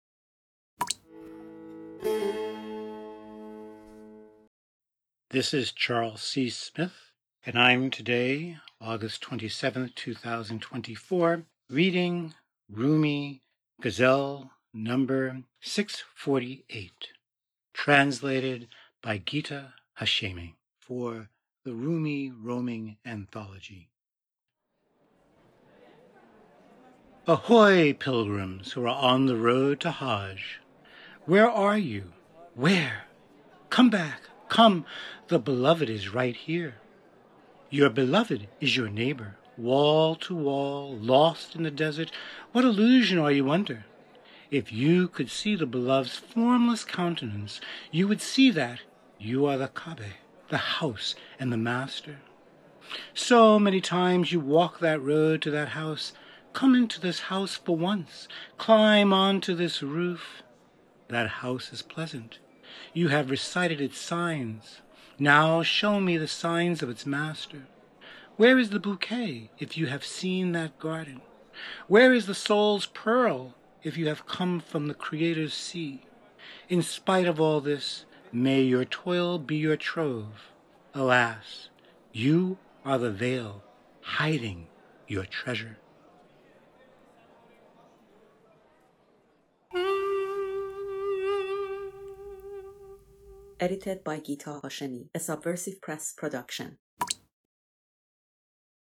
Rumi, Ghazal 648, Translation, Rumi roaming, Poetry